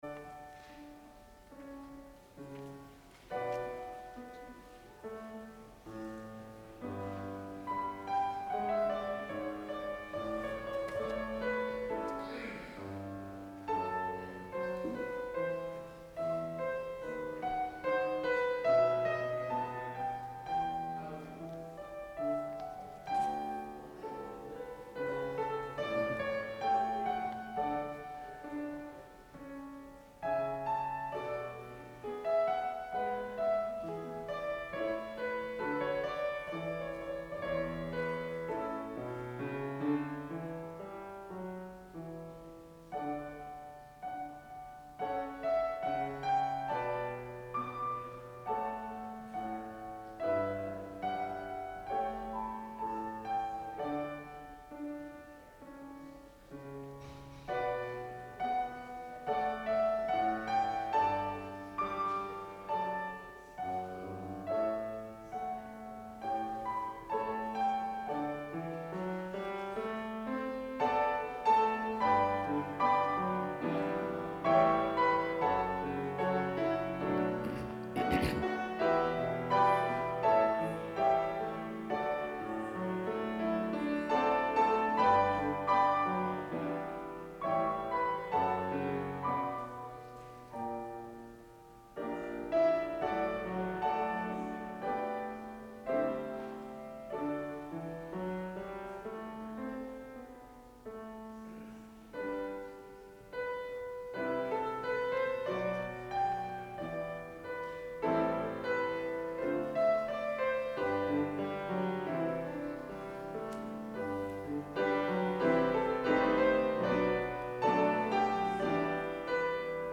Please click Here for audio recording of worship services.